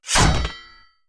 Melee Weapon Attack 7.wav